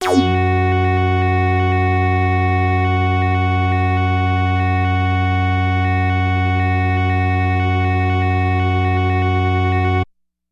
标签： F4 MIDI音符-66 雅马哈-CS-30L 合成器 单票据 多重采样
声道立体声